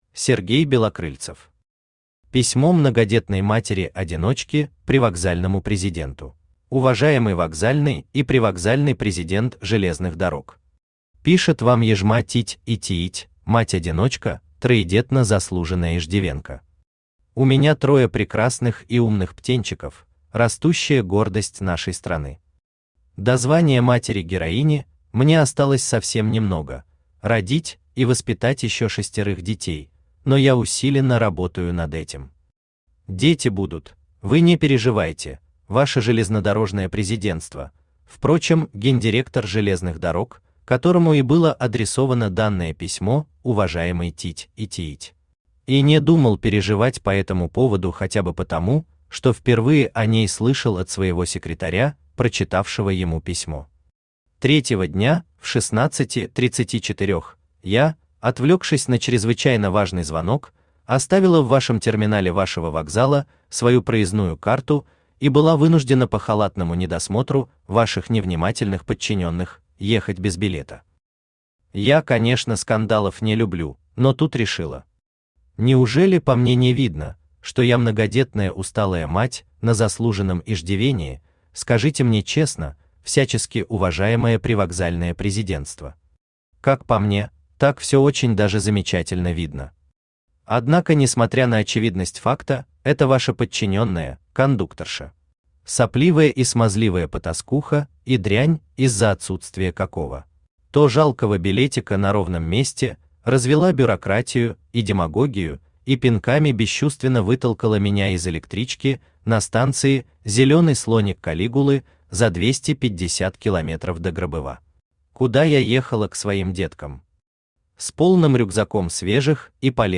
Аудиокнига Письмо многодетной матери-одиночки привокзальному президенту | Библиотека аудиокниг
Aудиокнига Письмо многодетной матери-одиночки привокзальному президенту Автор Сергей Валерьевич Белокрыльцев Читает аудиокнигу Авточтец ЛитРес.